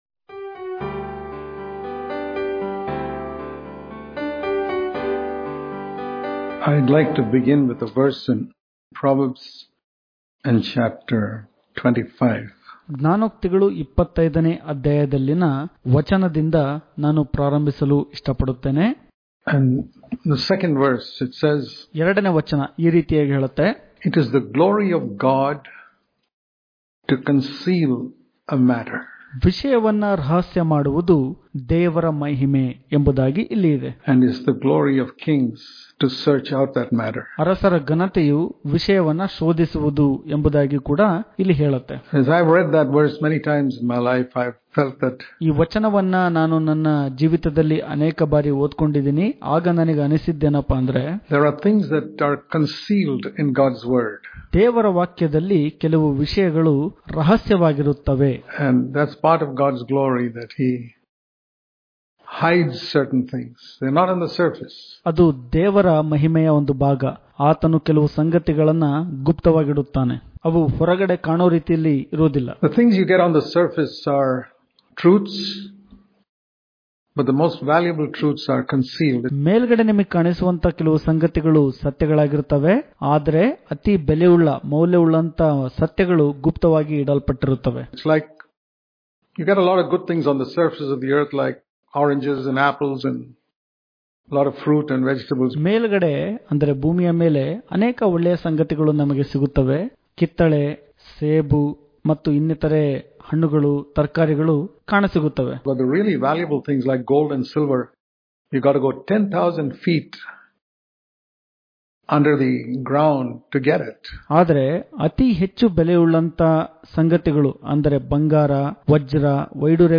June 29 | Kannada Daily Devotion | God Is A Rewarder Of Those Who Diligently Seek Him Daily Devotions